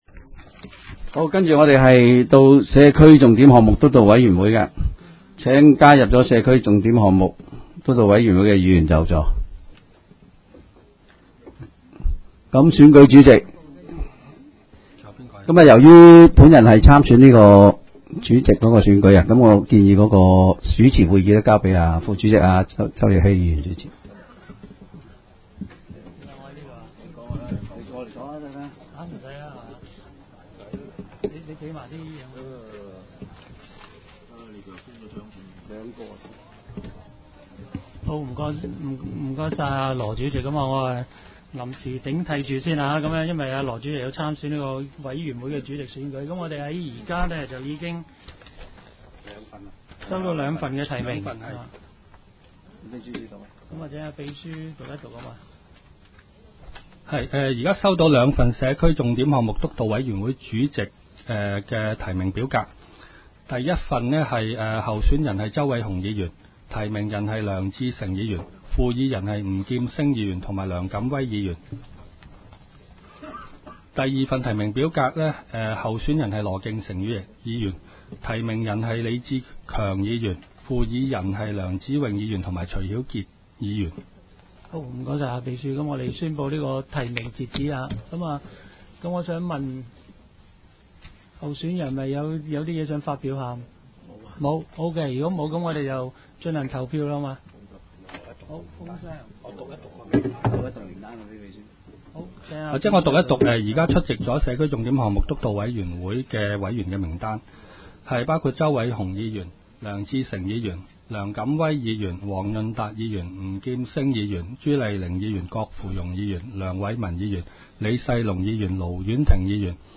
委员会会议的录音记录
社区重点及其他社区健康项目督导委员会第一次特别会议会议 日期: 2016-01-19 (星期二) 时间: 下午3时44分 地点: 香港葵涌兴芳路166-174号 葵兴政府合署10楼 葵青民政事务处会议室 议程 讨论时间 开会词 00:07:33 1. 选举社区重点项目督导委员会主席及副主席 00:15:26 全部展开 全部收回 议程: 开会词 讨论时间: 00:07:33 前一页 返回页首 议程:1.